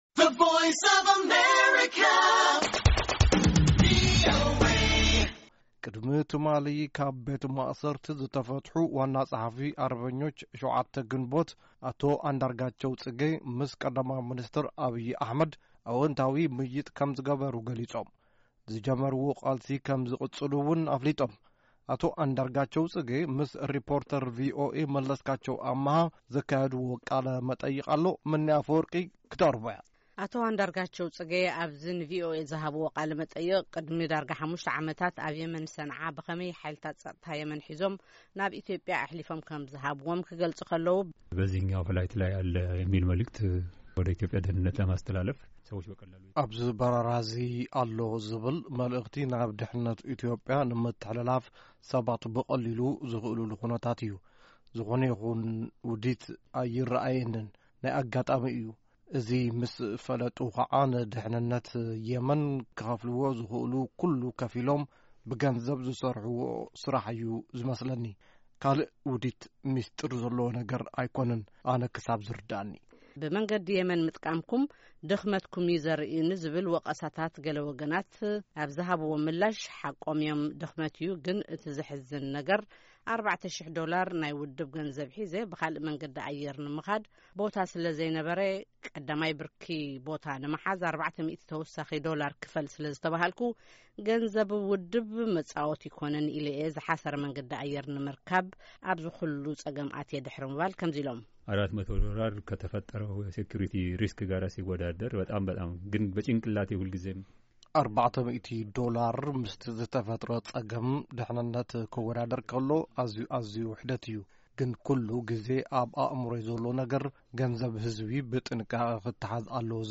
ቃለ- መጠይቅ ምስ አቶ ኣንዳርጋቸው ጽጌ